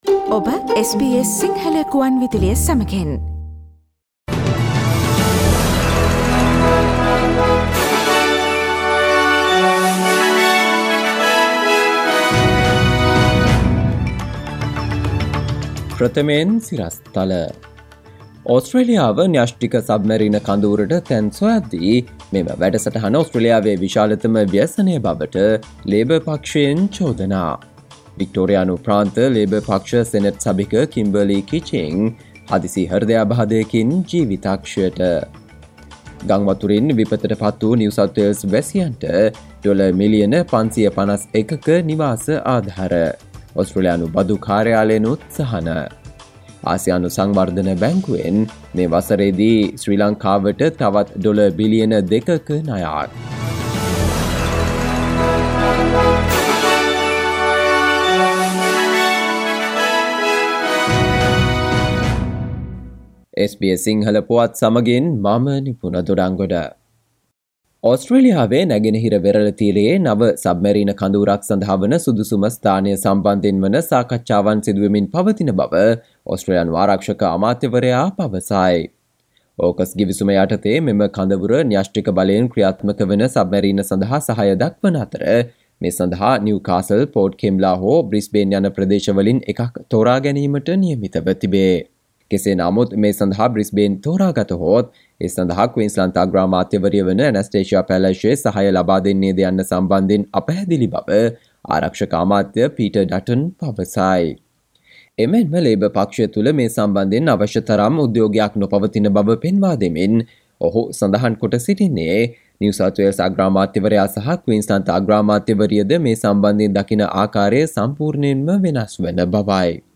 සවන්දෙන්න 2022 මාර්තු 11 වන සිකුරාදා SBS සිංහල ගුවන්විදුලියේ ප්‍රවෘත්ති ප්‍රකාශයට...